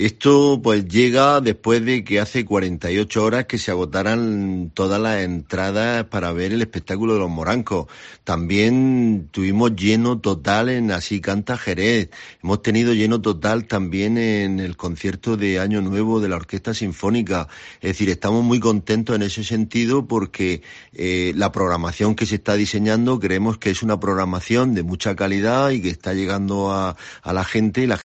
Santiago Parra, edil de Cultura del Ayuntamiento de Lorca